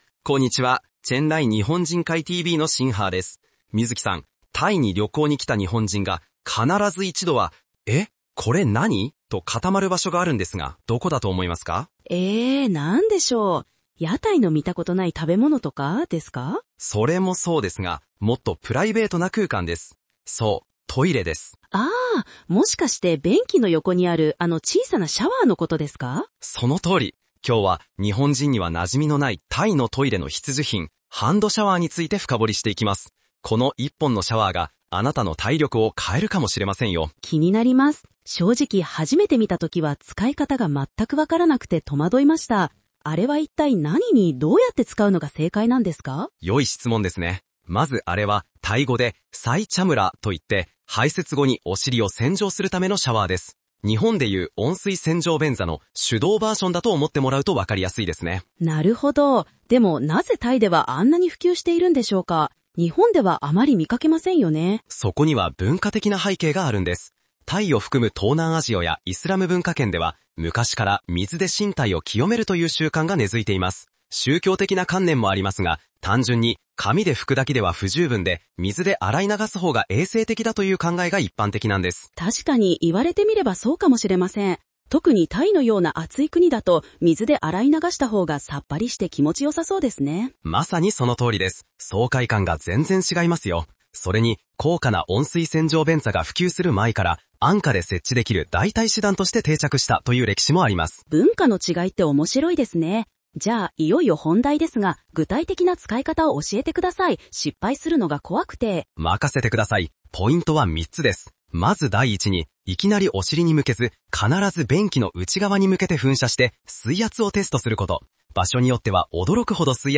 この記事の会話形式のPodcast音声です。